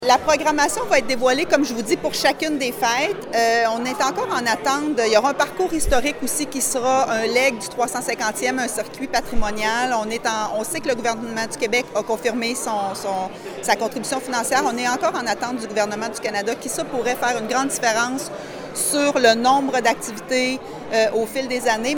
Une Fête nationale bonifiée en juin, la Fête des moissons en septembre et la fête de Lumières en décembre viennent compléter une programmation pour laquelle d’autres éléments pourraient s’ajouter comme l’indique la mairesse Geneviève Dubois.